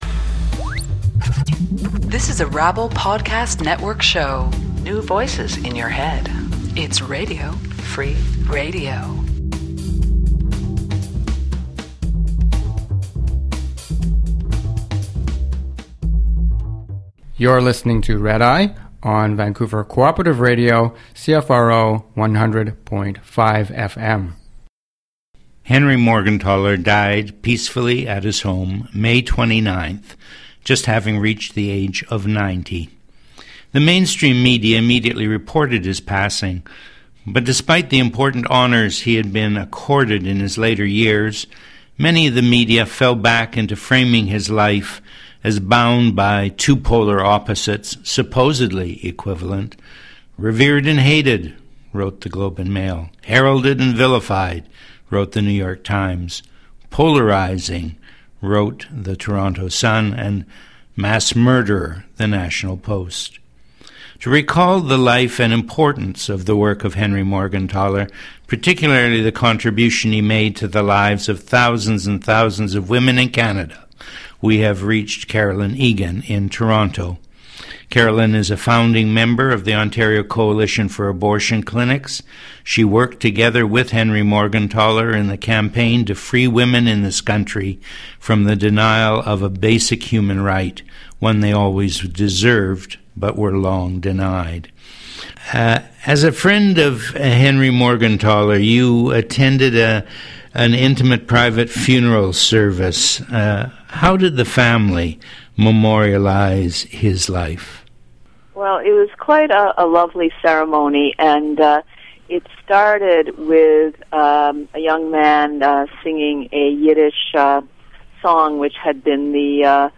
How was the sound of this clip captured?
Redeye is a three-hour live radio show produced by a collective based in Vancouver, B.C. If you live in the Vancouver area and are interested in working in alternative media, we’d love to hear from you.